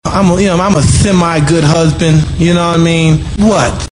Tags: sports radio